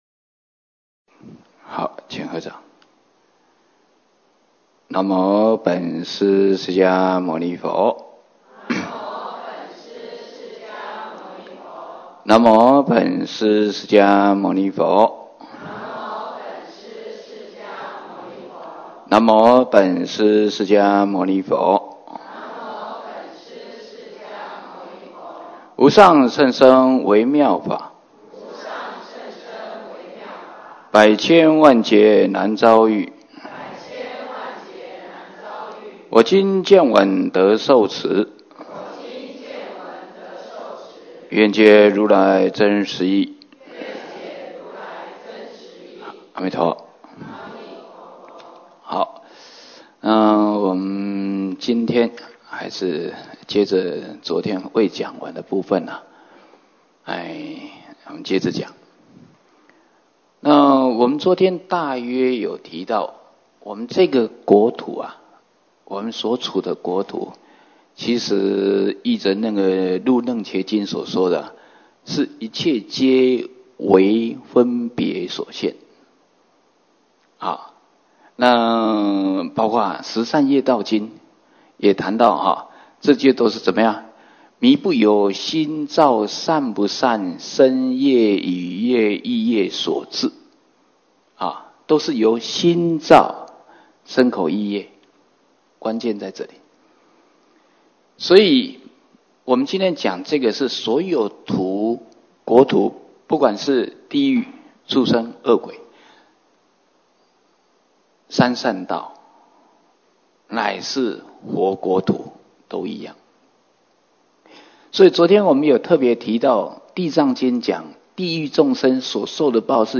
34佛七开示